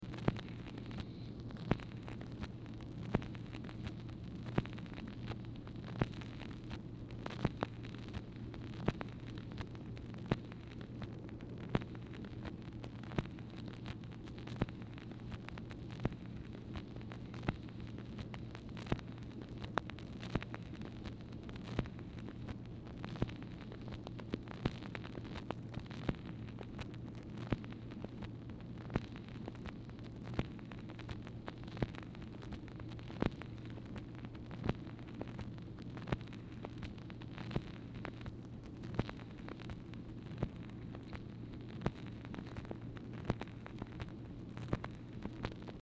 Telephone conversation # 3156, sound recording, MACHINE NOISE, 4/28/1964, time unknown | Discover LBJ
Telephone conversation
Format Dictation belt